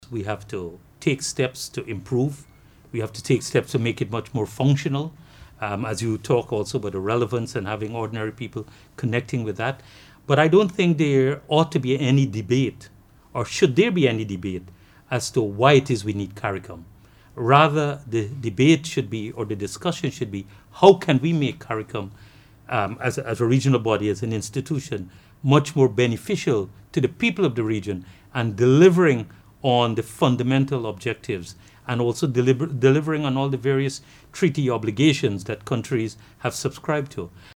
Dr. Carla Barnett, Secretary-General of Caricom, outlined the agenda for the 46th meeting, which includes discussions on the Caribbean Single Market and Economy (CSME), free movement, climate change, climate financing, energy, food security, and global and hemispheric issues, with a particular focus on Haiti.